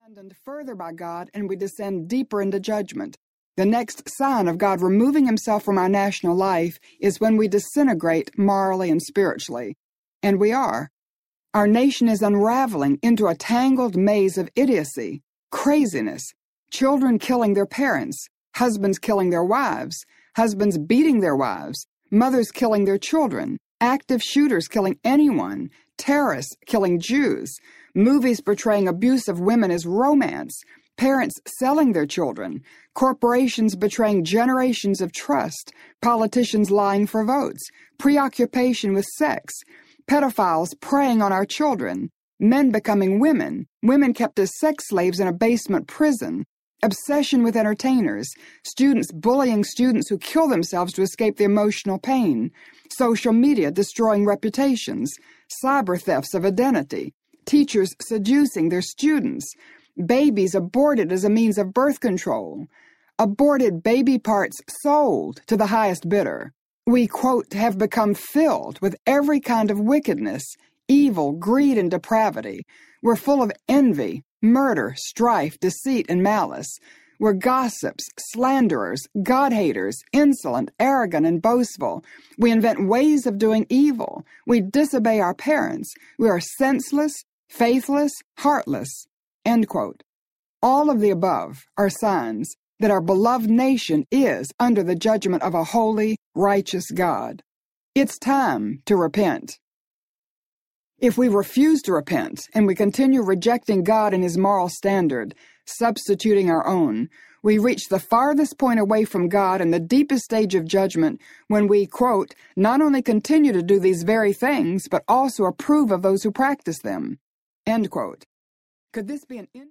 The Daniel Prayer Audiobook
6.17 Hrs. – Unabridged